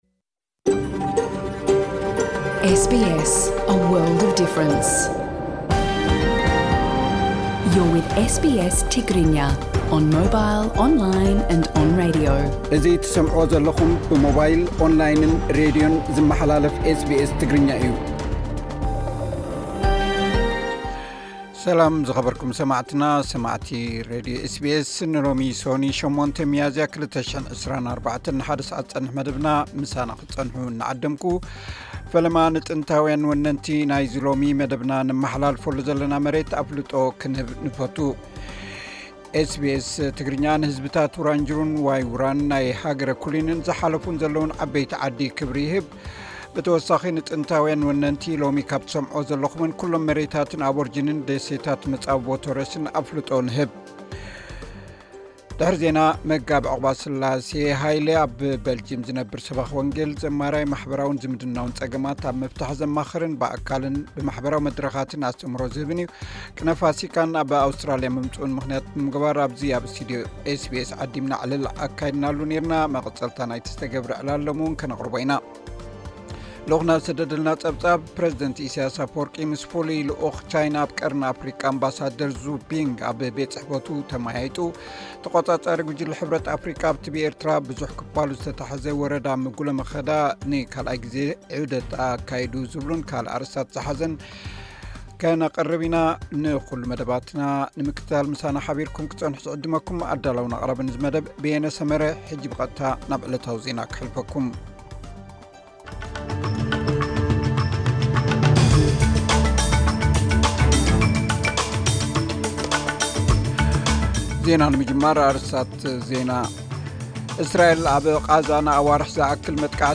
ቅነ ፋሲካን ኣብ ኣውስትራልያ ምምጽኡን ምኽንያት ብምግባር ኣብ’ዚ ኣብ ስቱድዮ ኤስቢኤስ ዓዲምና ዕላል ኣካይድናሉ’ ኔርና።